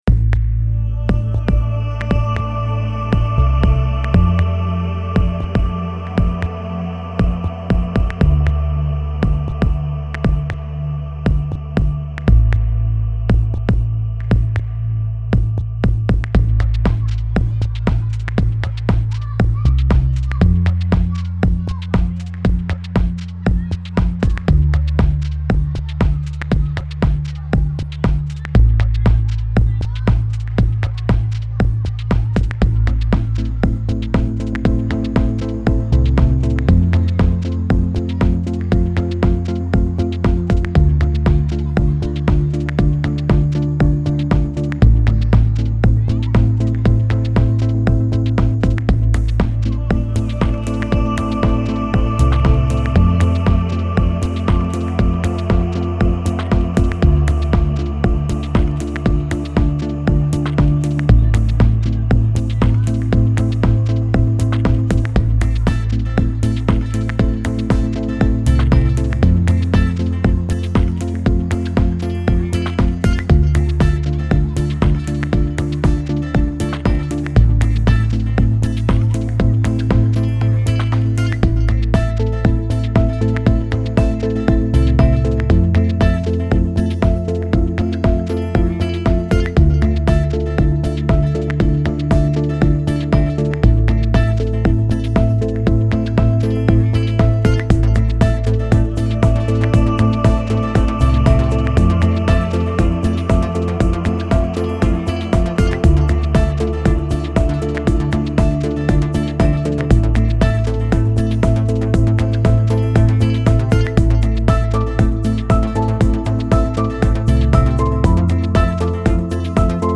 Genre: Chillout, Deep House.